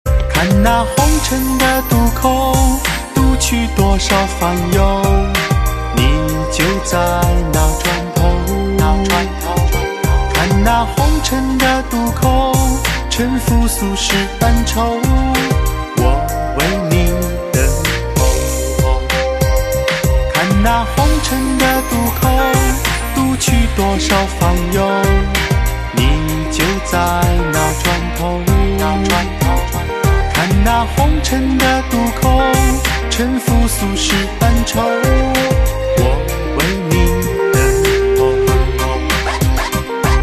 M4R铃声, MP3铃声, 华语歌曲 27 首发日期：2018-05-15 09:20 星期二